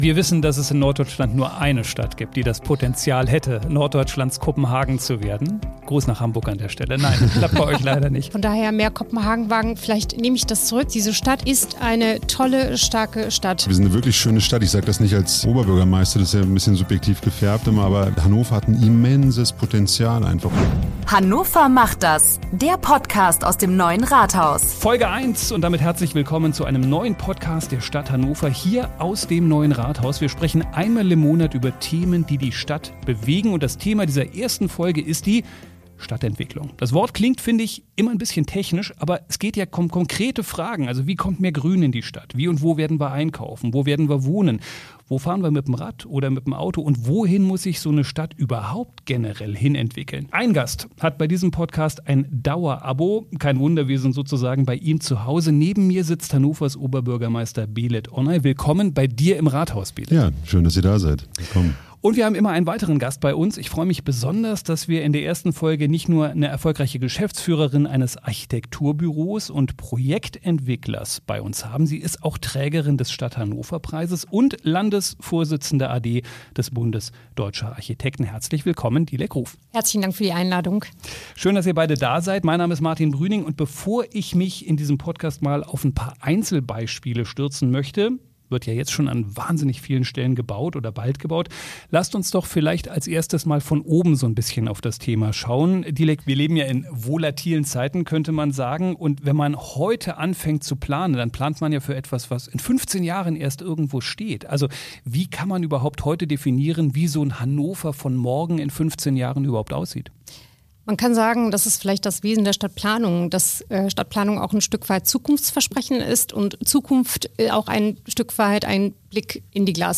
Eine spannende Diskussion über Mut, Tempo und die Zukunft der Stadt!